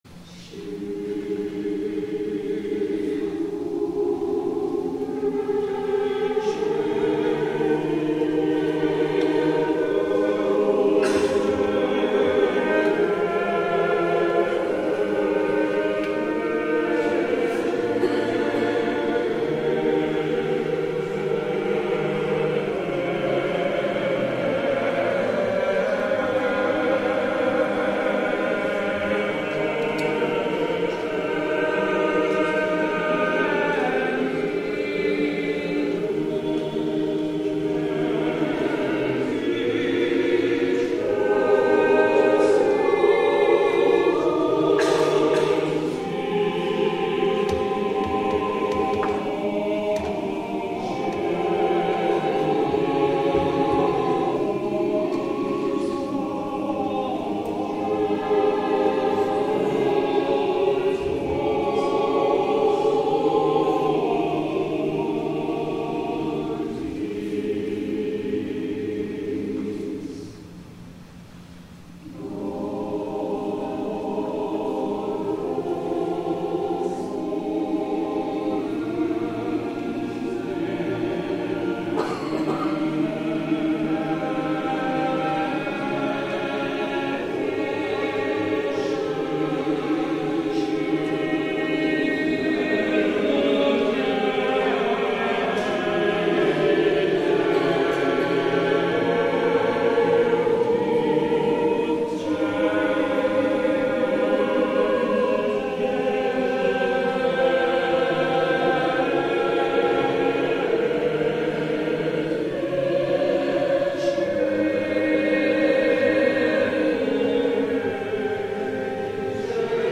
THE ANTHEM